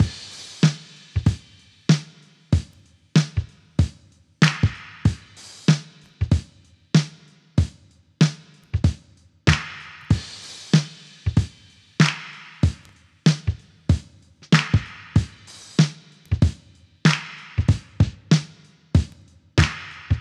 • 95 Bpm Drum Loop Sample G Key.wav
Free breakbeat - kick tuned to the G note. Loudest frequency: 899Hz
95-bpm-drum-loop-sample-g-key-mg0.wav